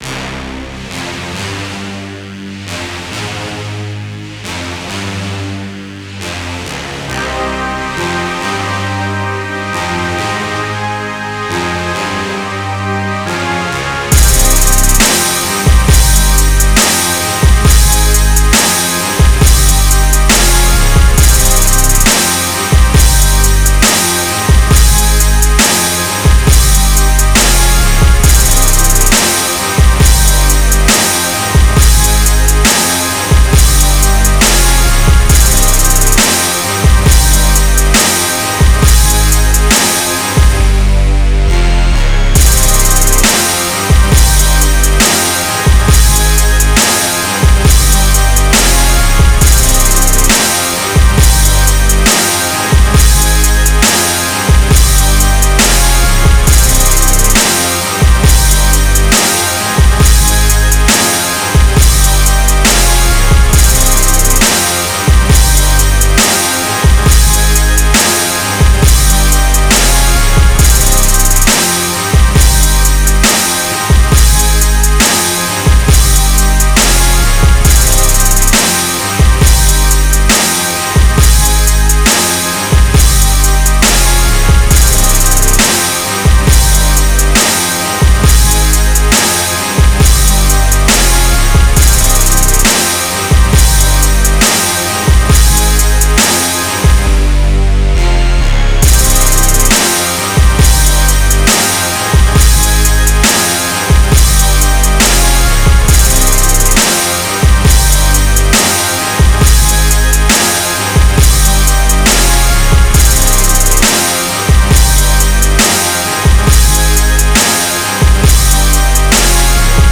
• 136BPM